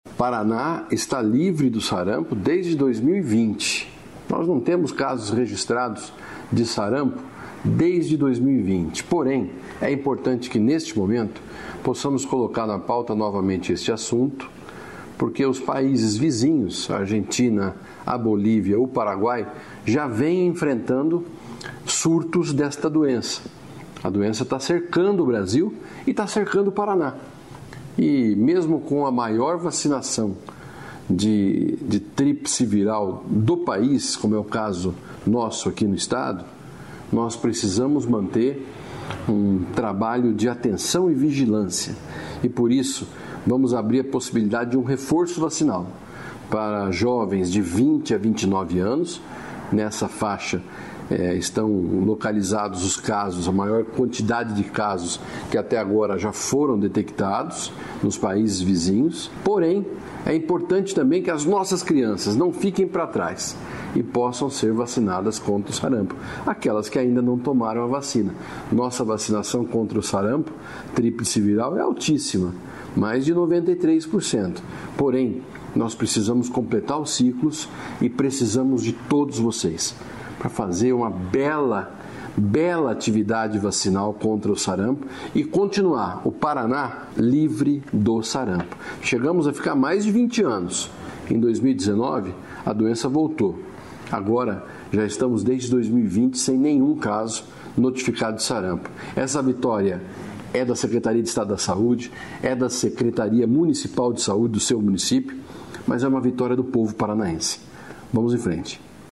Sonora do secretário da Saúde, Beto Preto, sobre a vacina contra o Sarampo